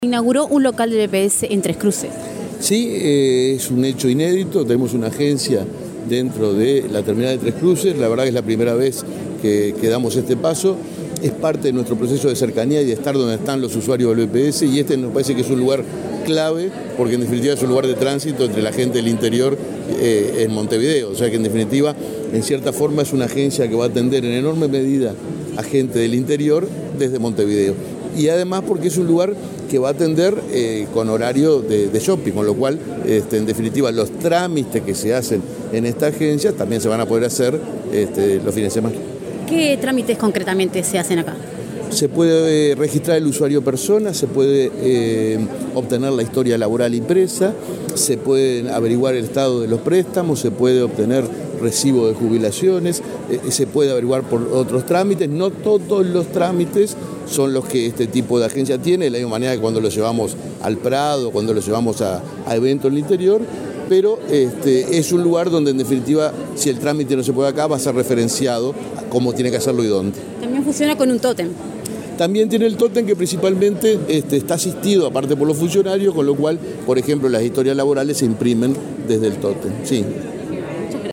Declaraciones del presidente del BPS, Alfredo Cabrera